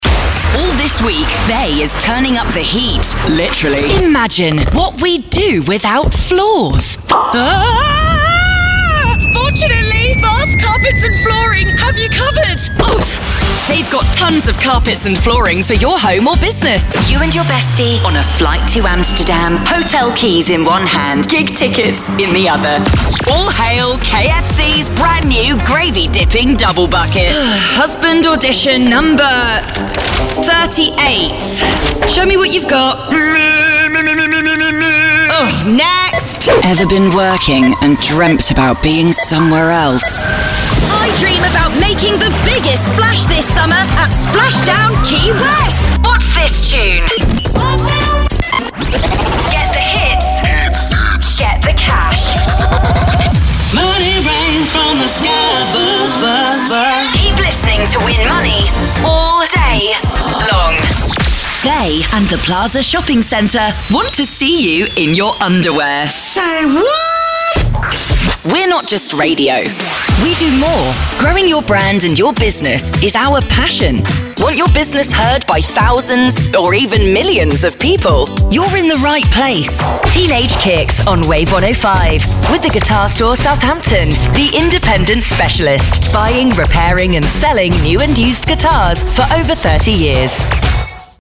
Radio Showreel
Her neutral British English accent is is warm, earthy, and grounded, with a flexible register that allows her to convincingly perform a wide range of ages.
Female
Neutral British
Confident
Upbeat